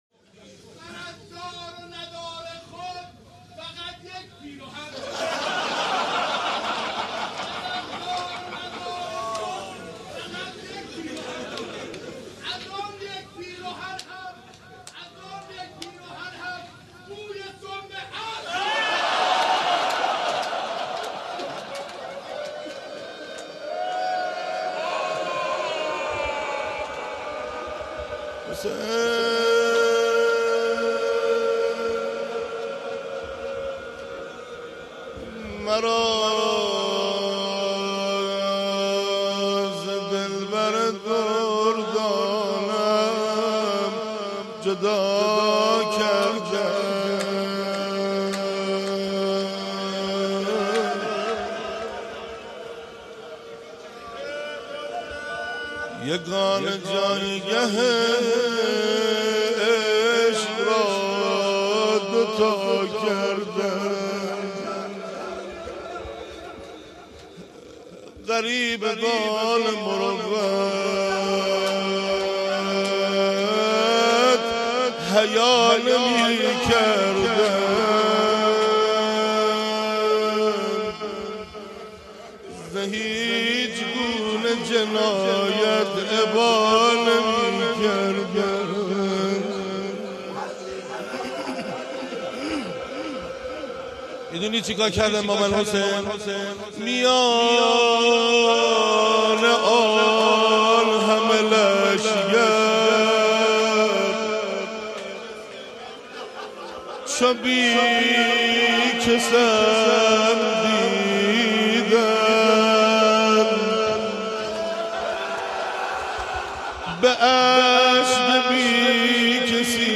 مرا ز دلبر دردانه ام جدا کردند | روضه